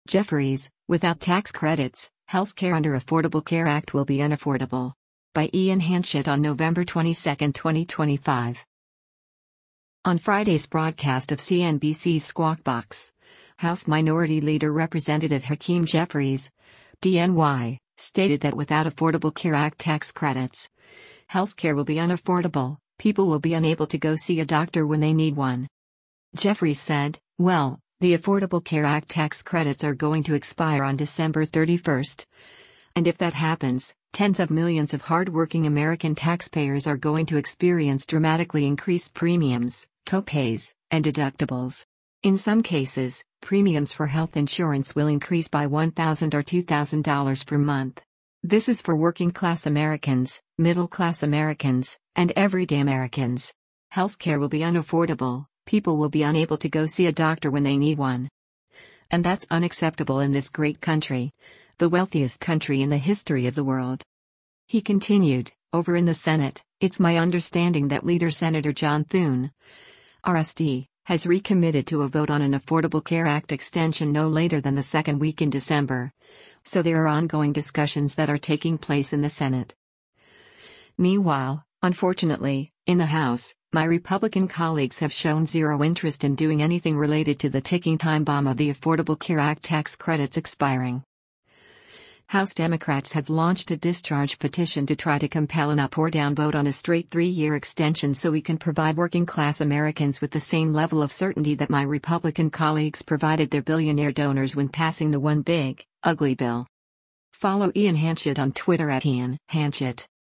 On Friday’s broadcast of CNBC’s “Squawk Box,” House Minority Leader Rep. Hakeem Jeffries (D-NY) stated that without Affordable Care Act tax credits, “Health care will be unaffordable, people will be unable to go see a doctor when they need one.”